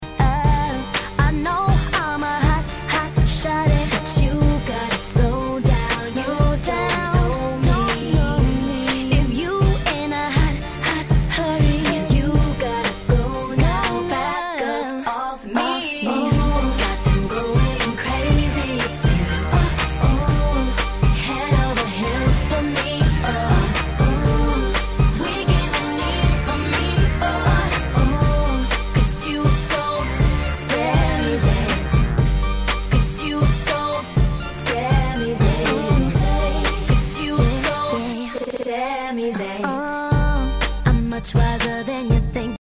DJ用にイントロにBreakがついて繋ぎ易くなってたりするアレです。
フロア受けバッチリのNice R&Bです！